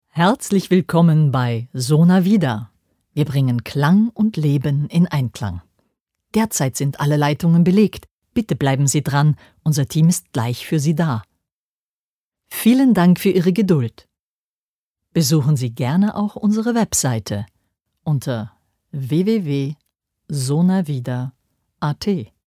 TELEFON-HOTLINE" - Demo-Aufnahme